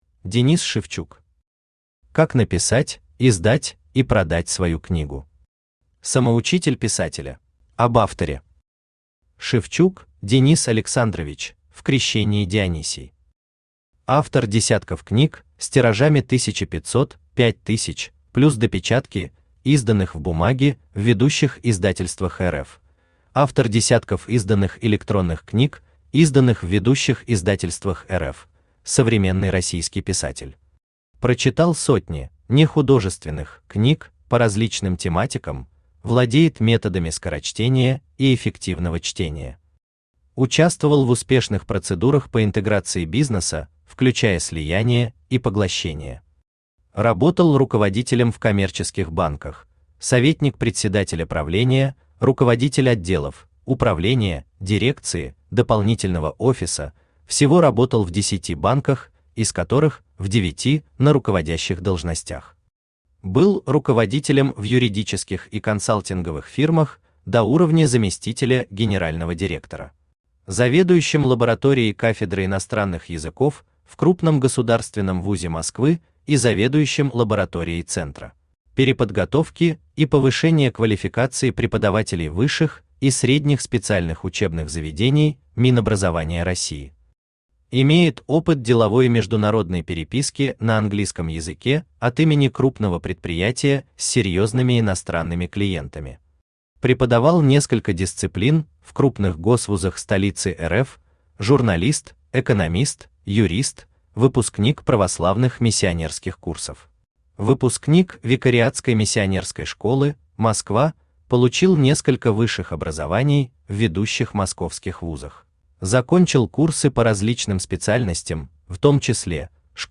Самоучитель писателя Автор Денис Александрович Шевчук Читает аудиокнигу Авточтец ЛитРес.